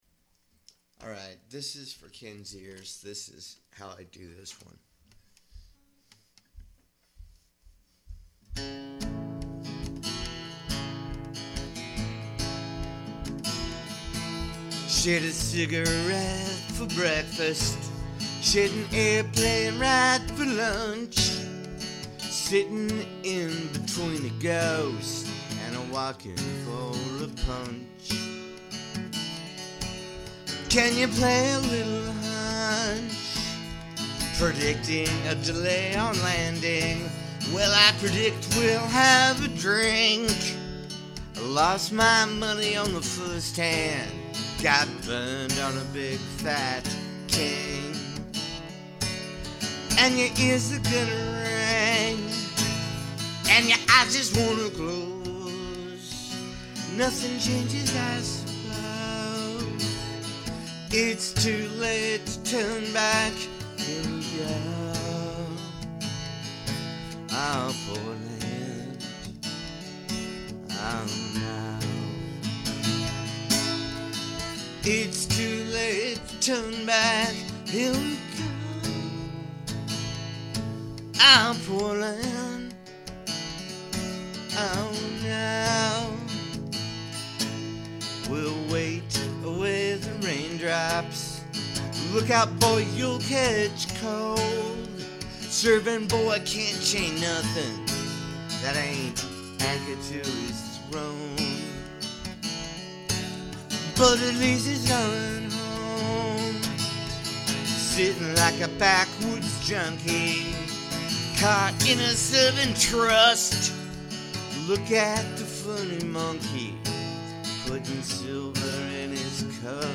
This is a live recording, just my voice and a guitar.